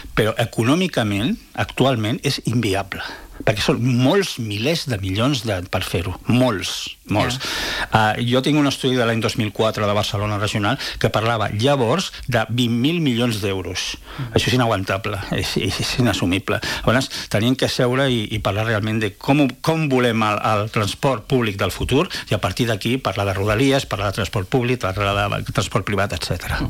Aquest dilluns, a l’entrevista del matinal de RCT